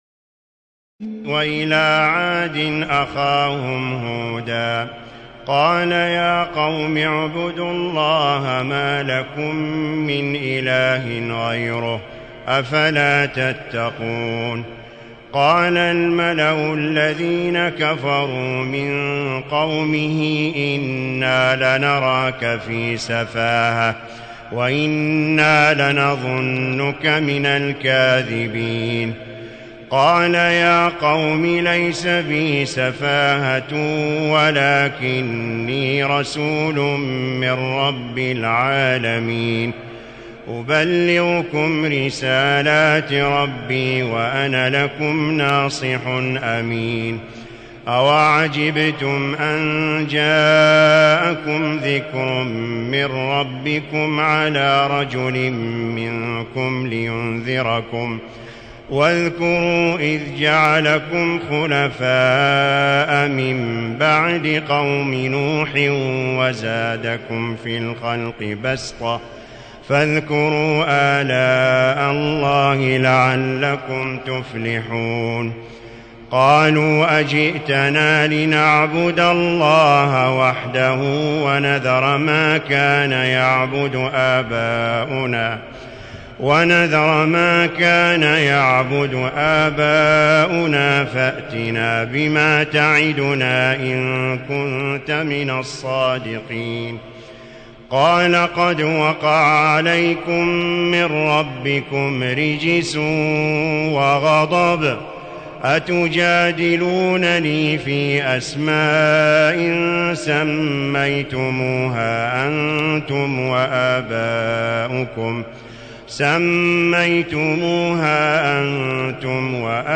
تراويح الليلة الثامنة رمضان 1437هـ من سورة الأعراف (65-162) Taraweeh 8 st night Ramadan 1437H from Surah Al-A’raf > تراويح الحرم المكي عام 1437 🕋 > التراويح - تلاوات الحرمين